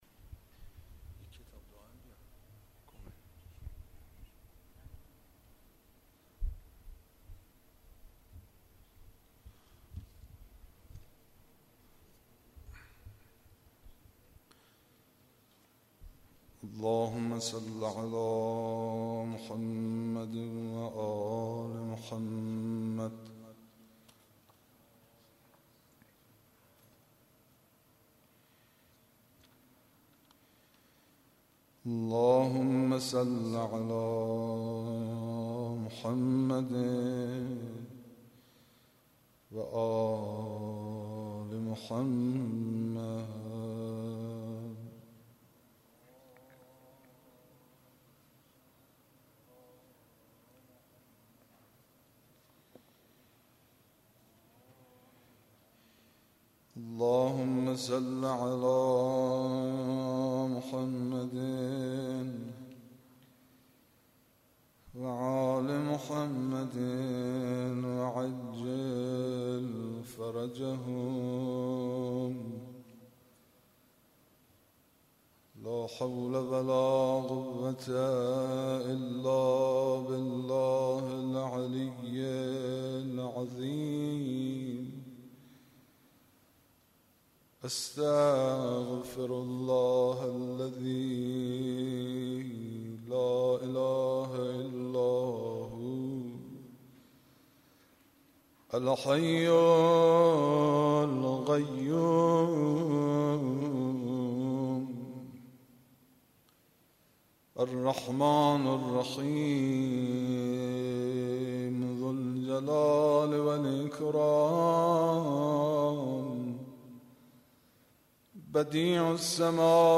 قرائت دعای کمیل